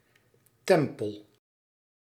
Ääntäminen
France: IPA: [tɑ̃pl]